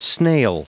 Prononciation du mot snail en anglais (fichier audio)
Prononciation du mot : snail